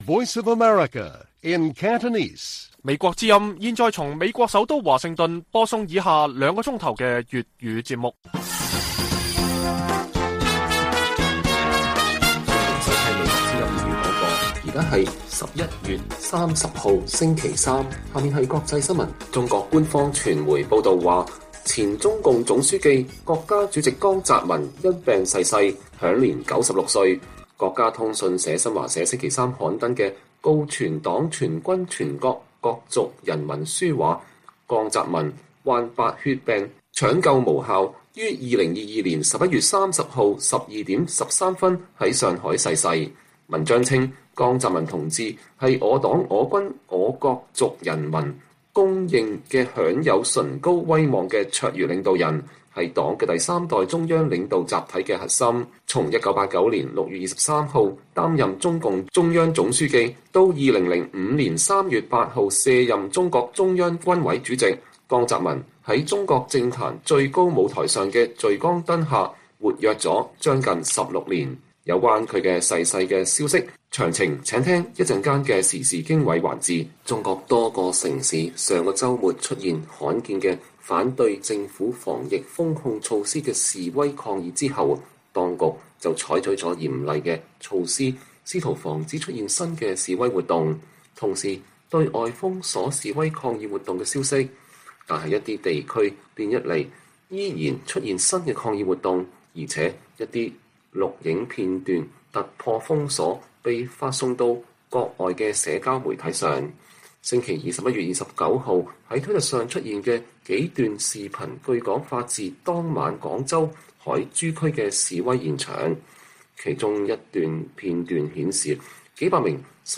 粵語新聞 晚上9-10點：中前中共總書記、國家主席江澤民因病逝世